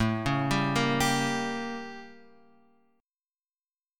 A7b9 chord {5 4 5 3 x 3} chord